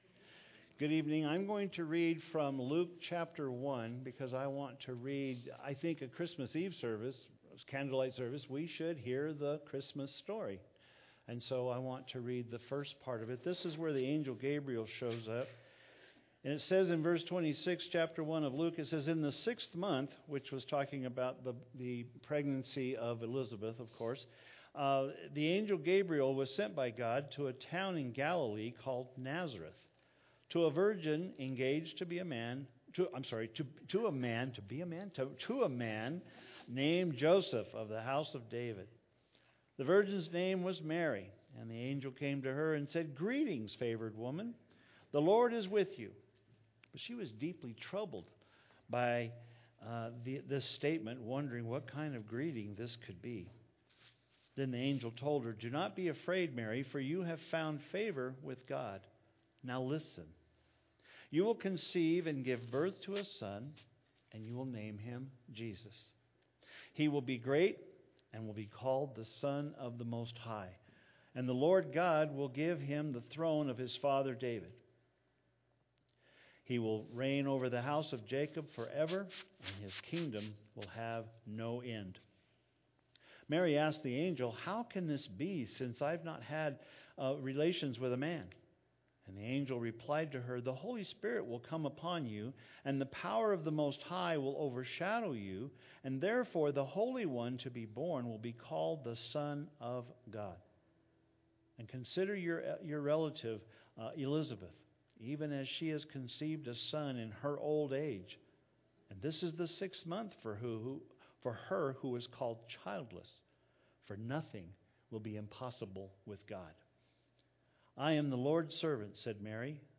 Christmas Eve Candlelight Service - Voice of God - Jesus the Christ